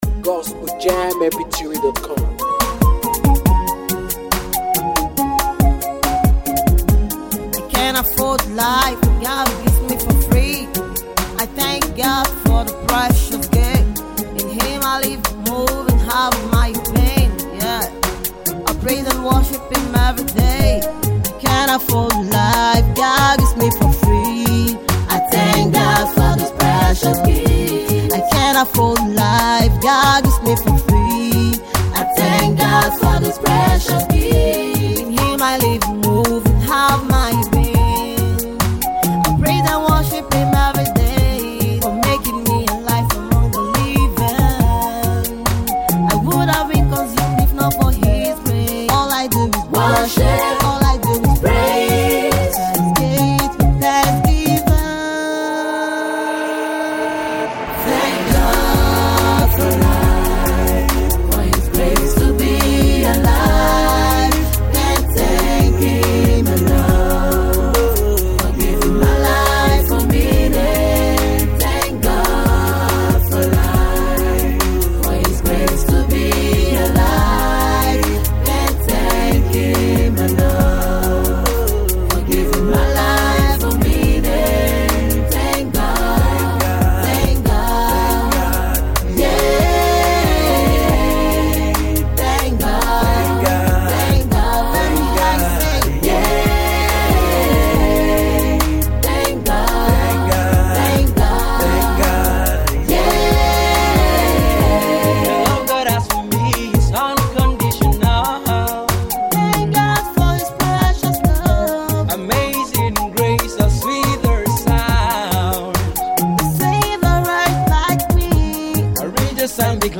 Fast rising Gospel music minister..